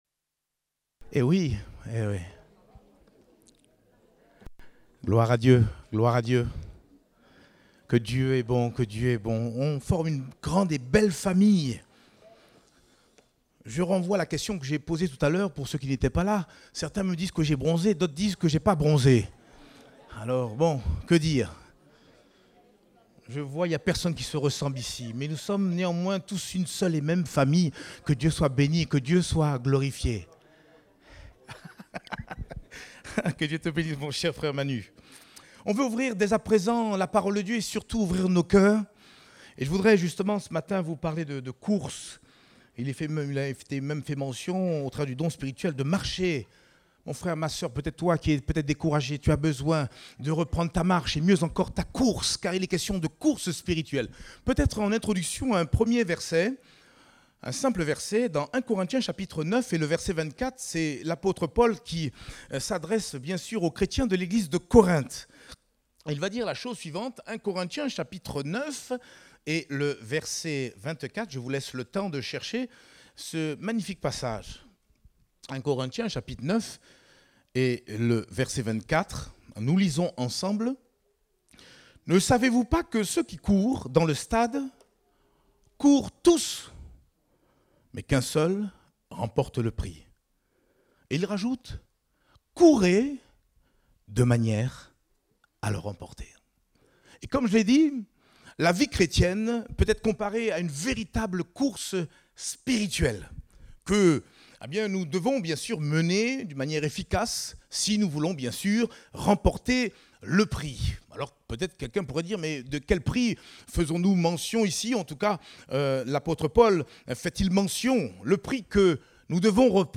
Date : 16 juillet 2023 (Culte Dominical)